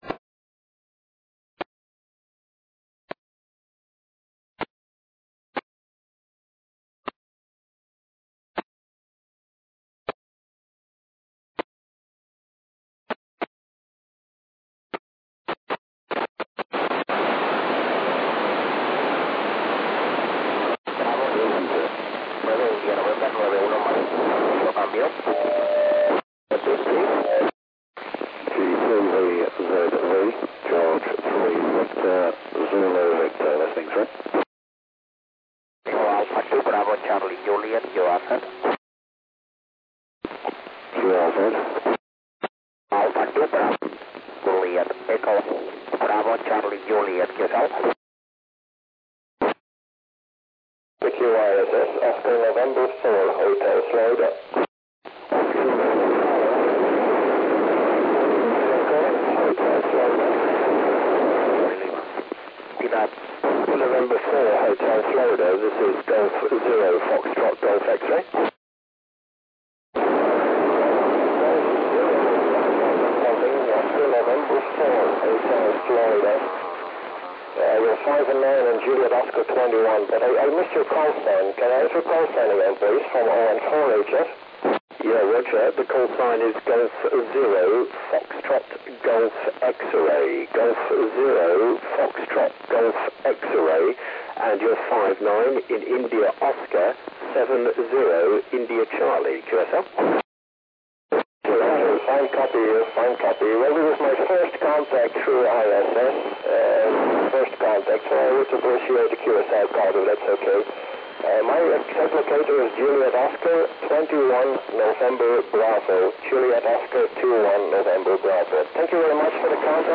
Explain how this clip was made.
ISS Voice via Repeater U/v on Sunday FEB 22 2009 High pass over IM77OU, Cordoba - Spain. Many stations working.